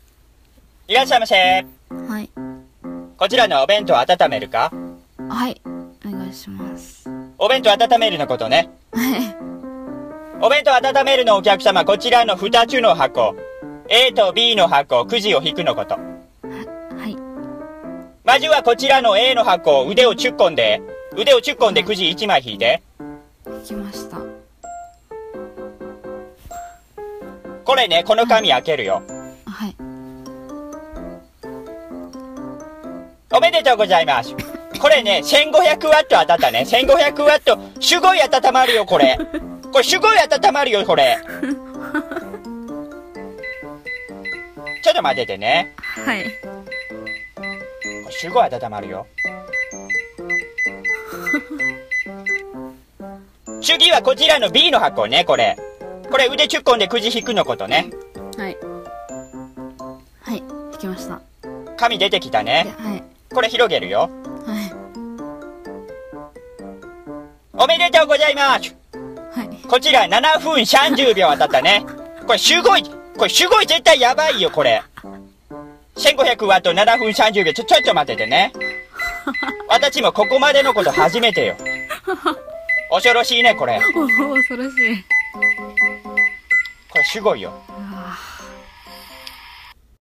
【コンビニ声劇】